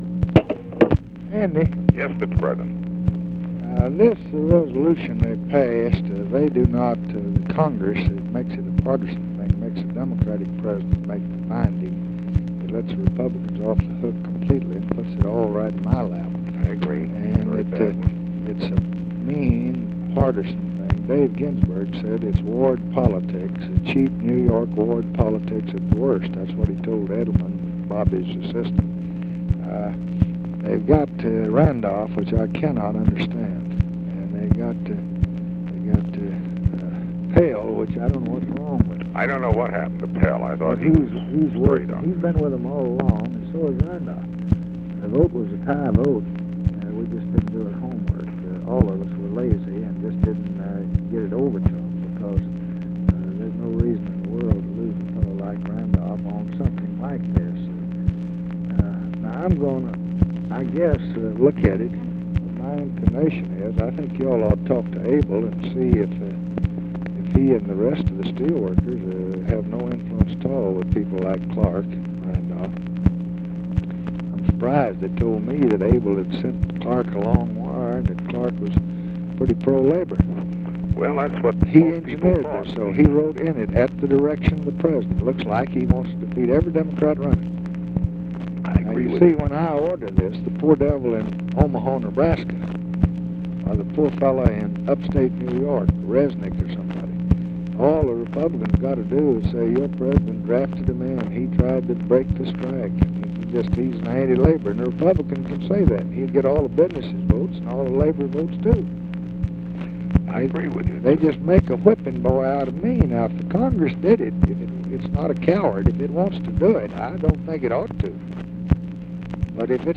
Conversation with ANDREW BIEMILLER, August 2, 1966
Secret White House Tapes